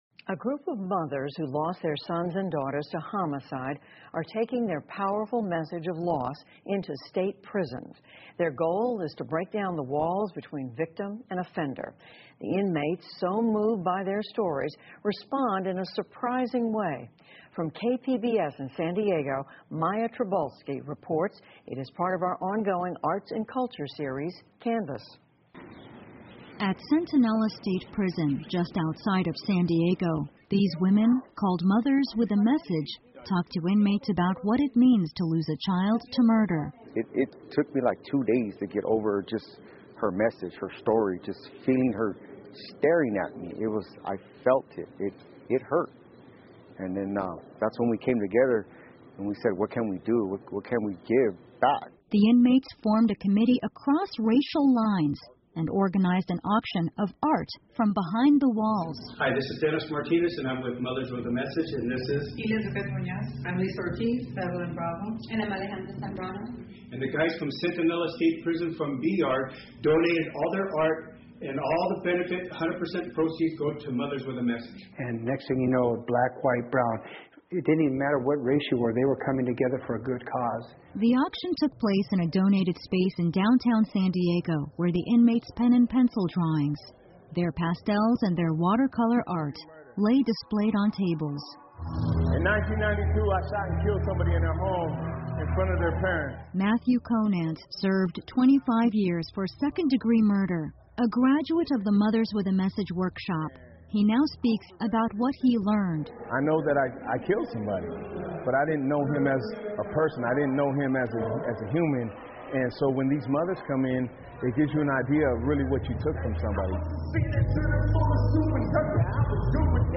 PBS高端访谈:拉动罪犯和受害者之间的关系 听力文件下载—在线英语听力室